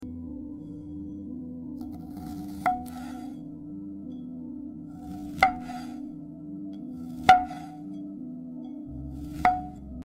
🍑 Plum Perfection ASMR — sound effects free download
Every bite. Every slice. Pure audio bliss. Let the crisp snap and juicy textures guide you into calm.